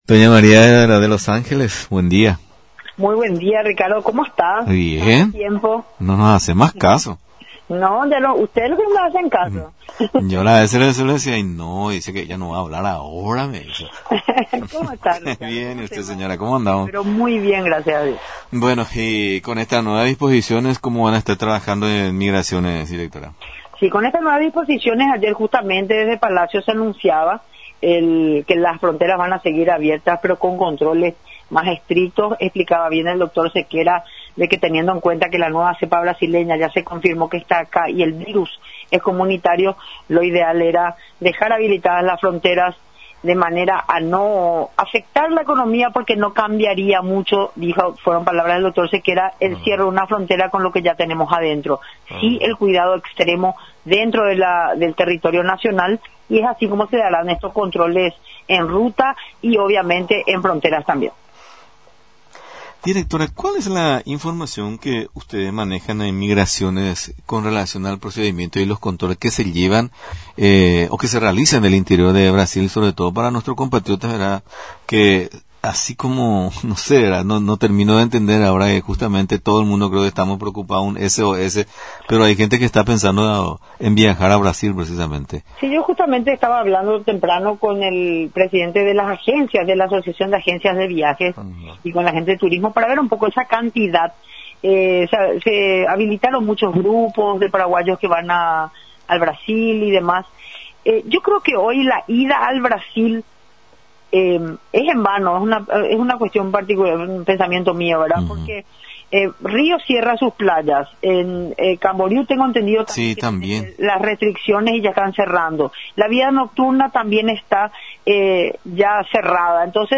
Ángeles Arriola, Directora de Migraciones en comunicación con nuestra emisora dijo que, con las nueva disposiciones anunciadas por el Gobierno las fronteras seguirán abiertas con controles más estrictos, teniendo en cuenta que la nueva cepa brasileña del virus, ya se confirmó que se encuentra acá, por ese motivo no incide mucho cerrar las fronteras.
Rio de Janeiro cierra sus playas, Camboriú lo mismo, la vida nocturna también, hoy irse al Brasil no constituye ninguna atracción por eso exhortamos a todos que se quede en casa. Solo aquellas personas que compraron con antelación algún paquete turísticos, a ir y a cuidarse muchísimo, manifestó nuestra entrevistada.